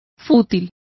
Complete with pronunciation of the translation of futile.